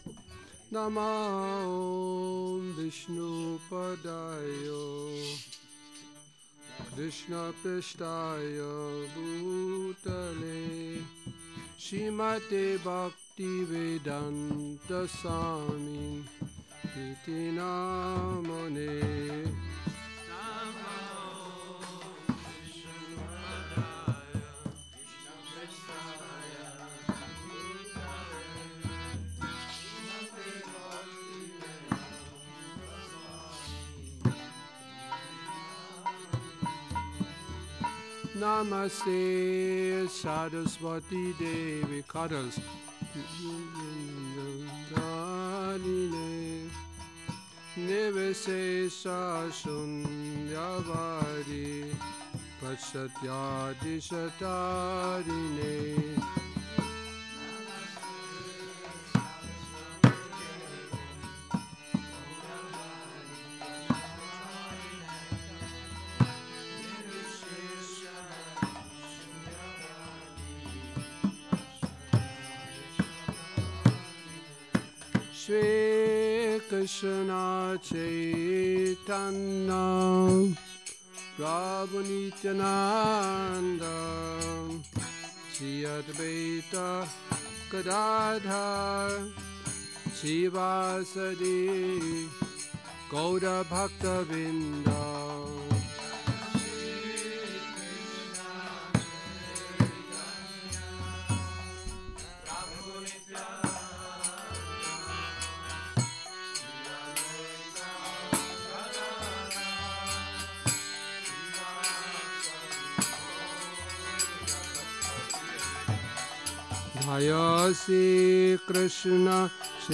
Kírtan Nedělní program